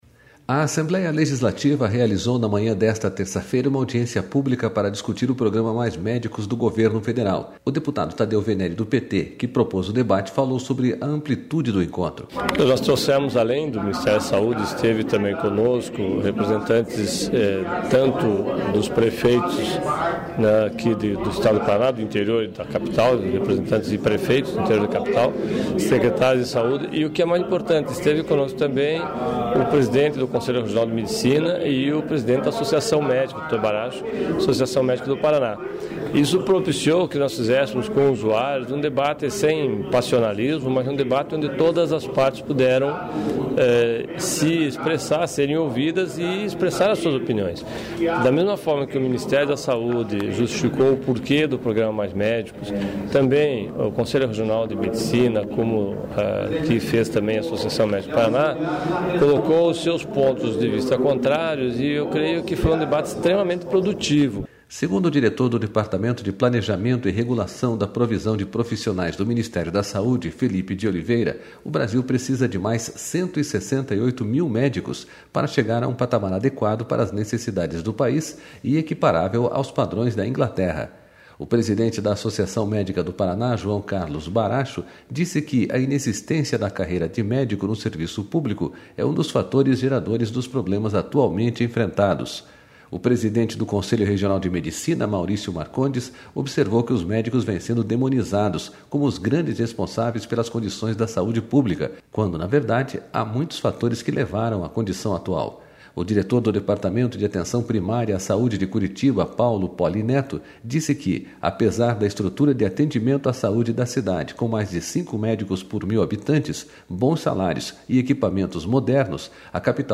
A Assembleia Legislativa realizou na manhã desta terça-feira uma audiência pública para discutir o Programa Mais Médicos, do Governo Federal.//O deputado Tadeu Veneri, do PT, que propôs o debate, falou sobre a amplitude do encontro.//SONORA VENERISegundo o diretor do Departamento de Planejamento e R...